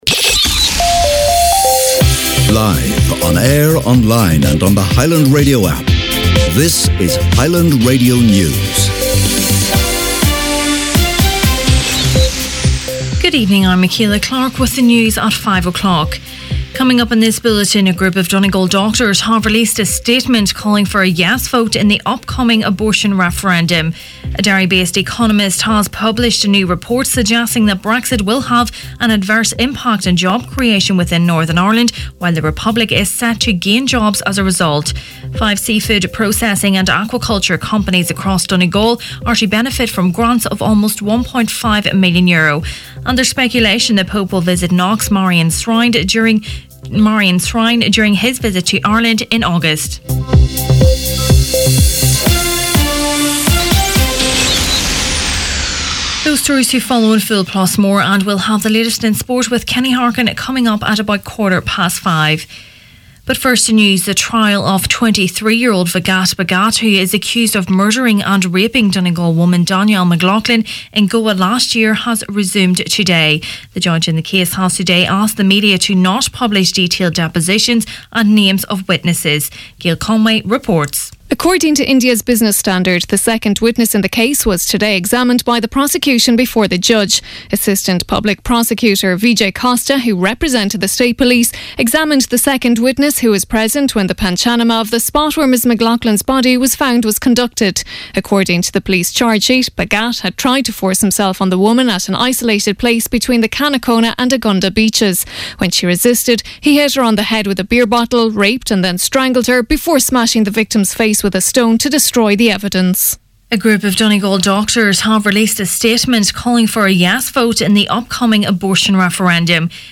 Main Evening News, Sport and Obituaries Tuesday 24th April